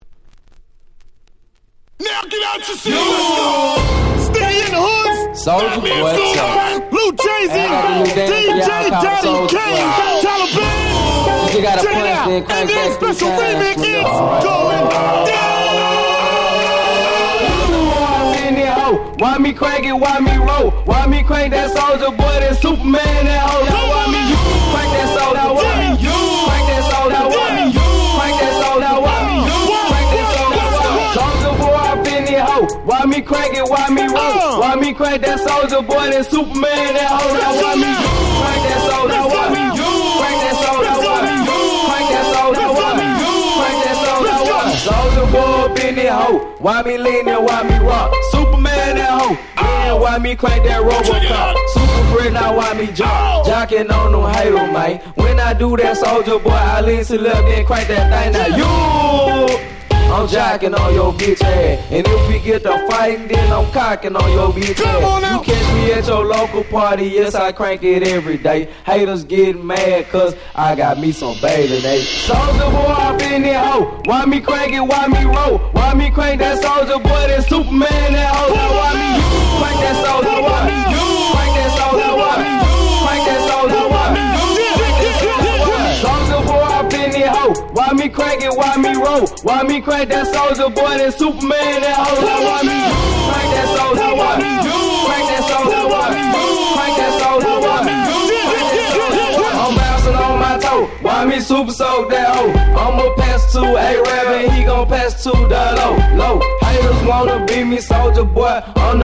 07' Super Hit Southern Hip Hop !!